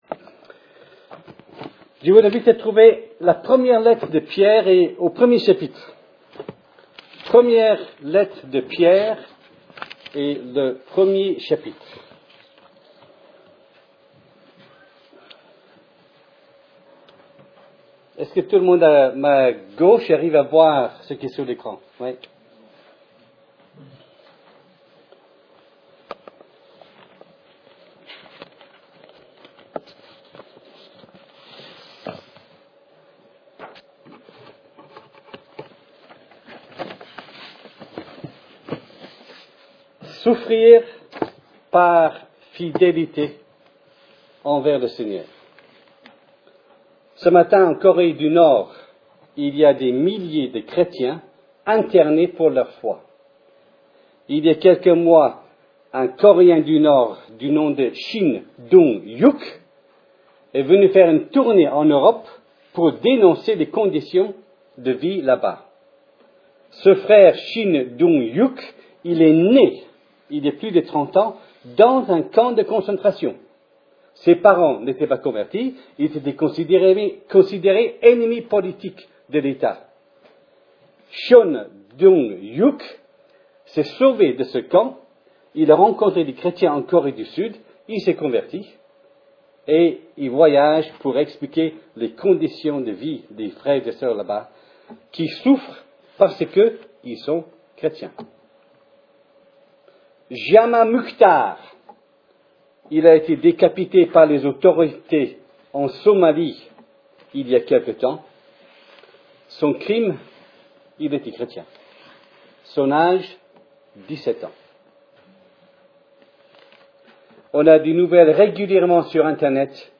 Type De Service: Culte Dimanche